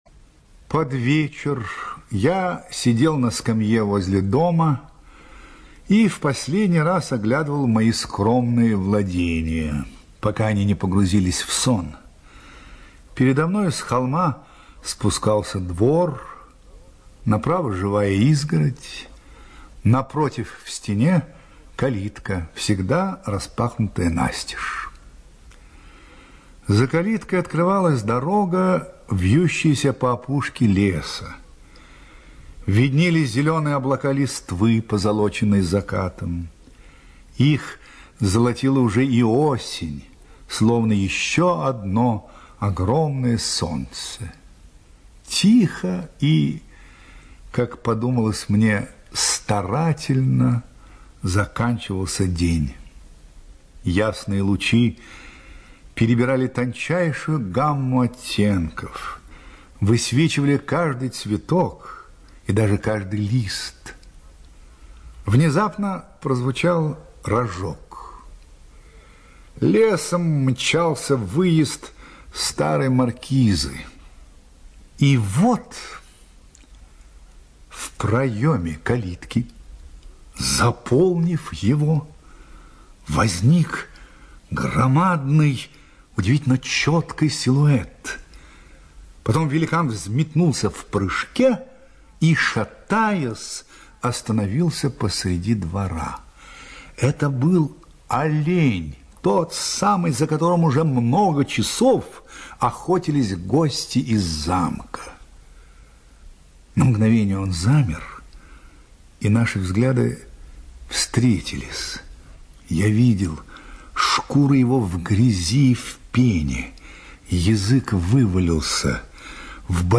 ЧитаетПлятт Р.